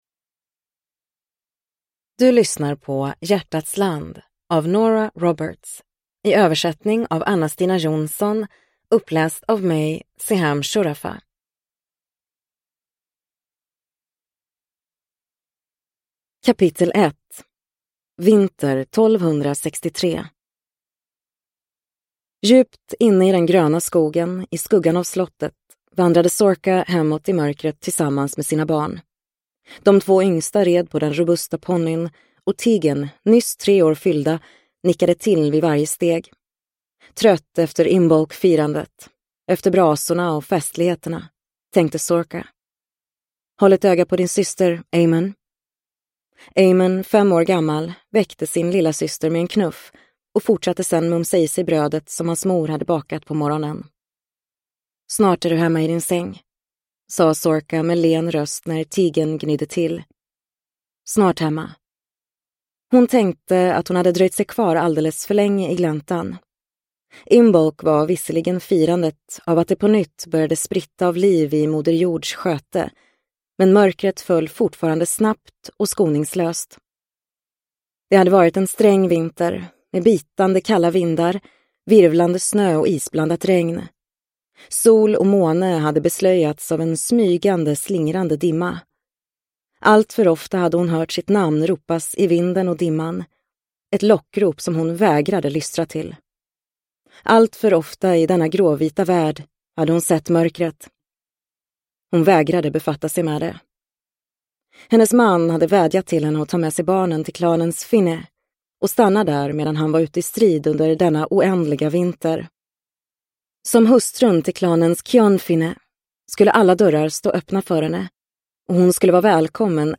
Dark Witch (svensk utgåva) – Ljudbok – Laddas ner